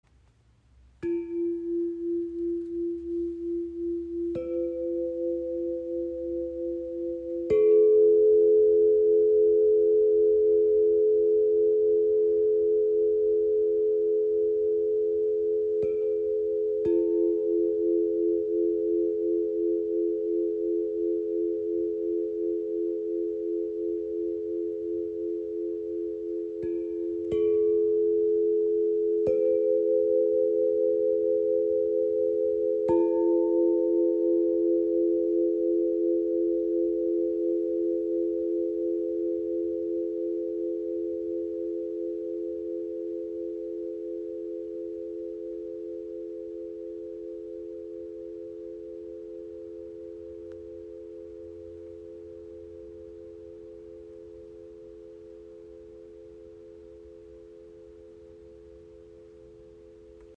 • Icon Drei harmonische Töne für weiche und zugleich kraftvolle Klangteppiche
Chordium L50 Klangröhren F Major | F4-A4-C in 432 Hz |
F Dur (F A C): erdend, beruhigend, stabilisierend